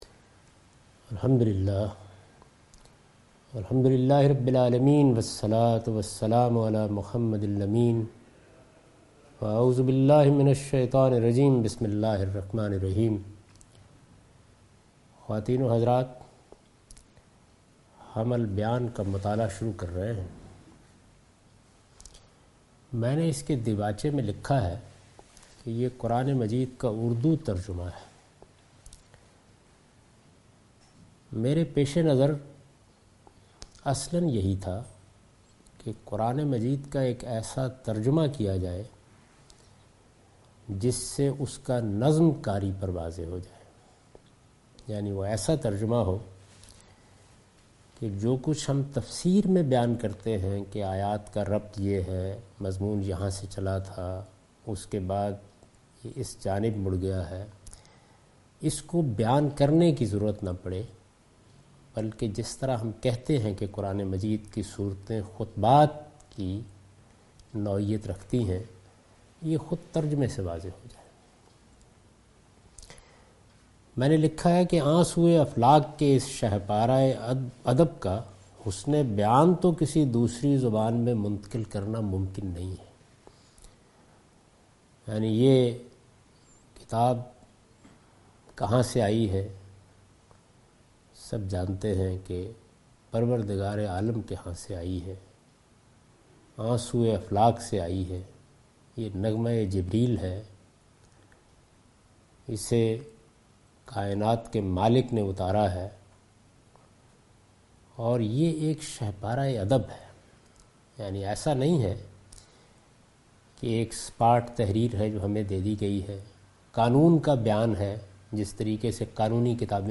A comprehensive course on Quran, wherein Javed Ahmad Ghamidi teaches his tafseer ‘Al Bayan’.